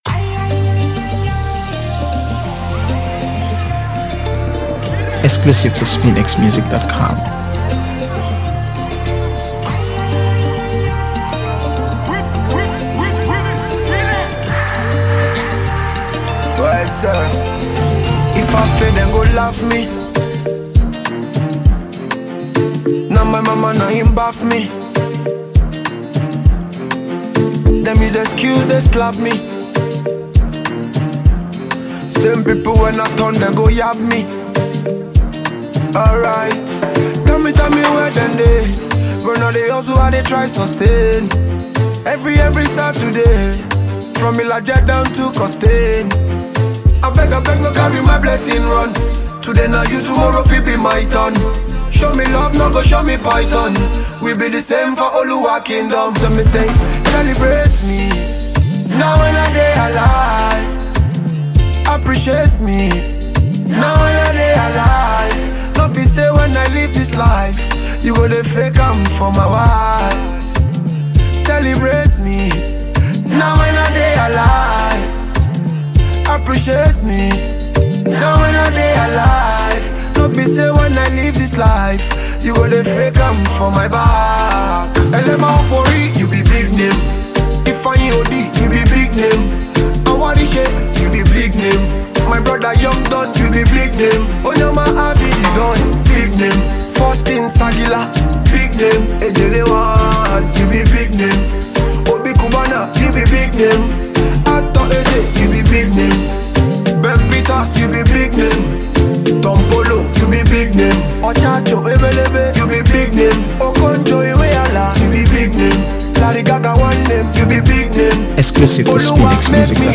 reggae-dancehall